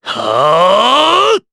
Clause_ice-Vox_Casting4_jp.wav